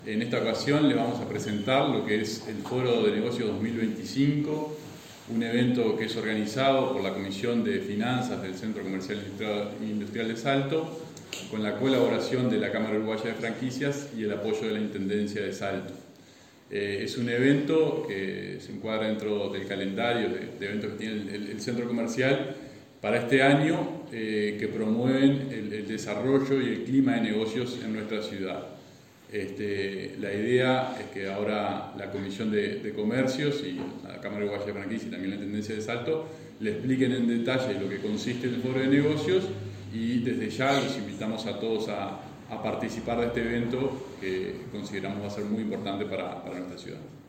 Este martes 29 de junio se realizó la conferencia de prensa de lanzamiento del Foro de Negocios Salto 2025, que se llevará a cabo el próximo 12 de agosto en el Centro Comercial e Industrial.